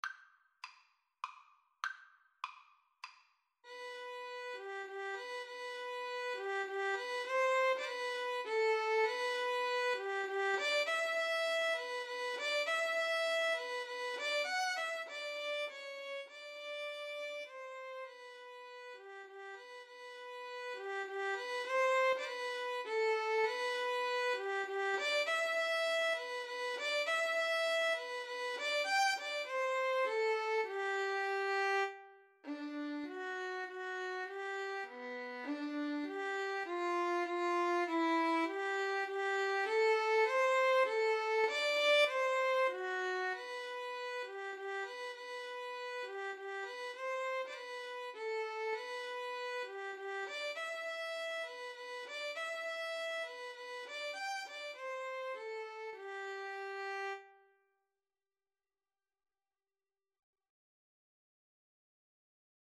Moderato
Classical (View more Classical Violin-Cello Duet Music)